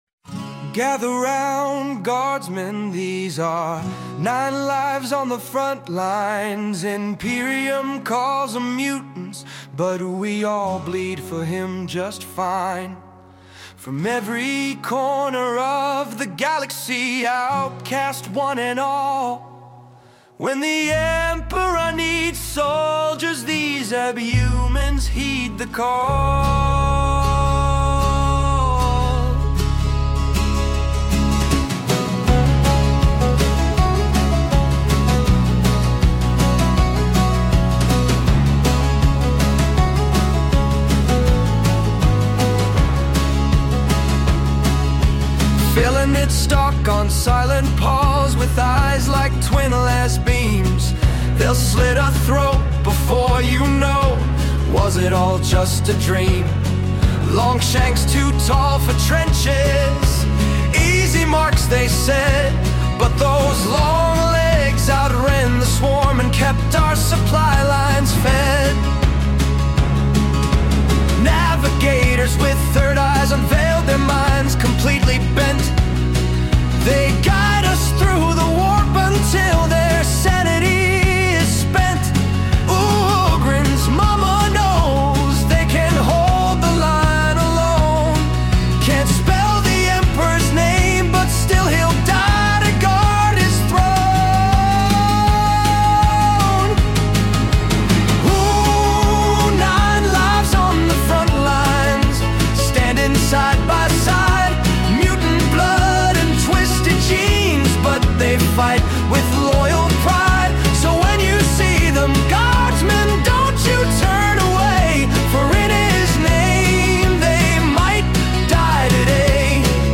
Warhammer 40k Abhumans inspired song